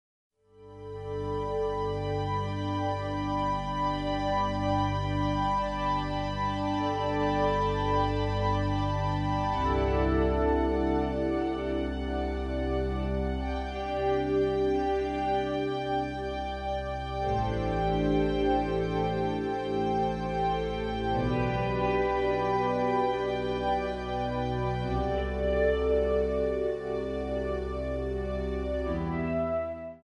G/A/B
MPEG 1 Layer 3 (Stereo)
Backing track Karaoke
Country, Duets, 1990s